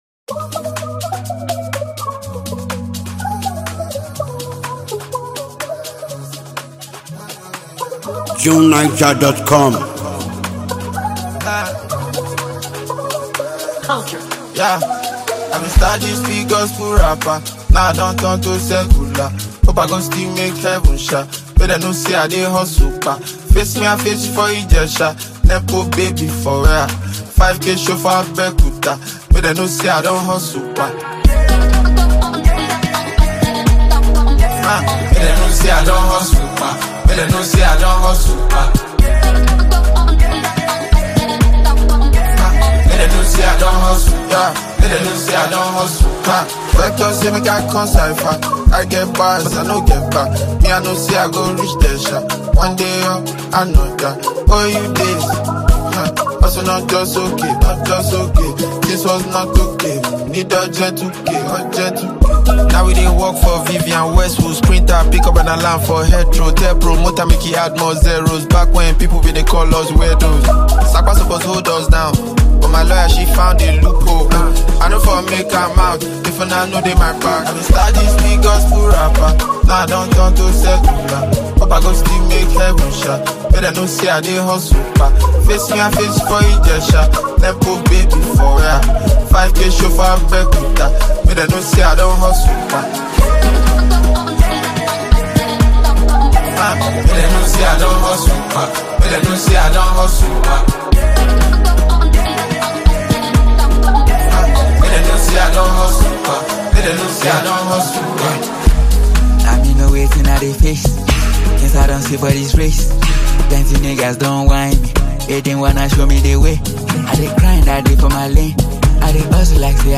heavyweight rap maestro and songwriter